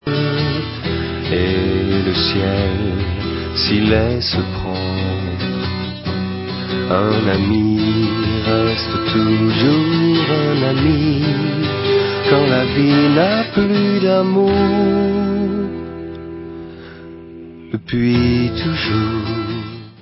12 string guitar versio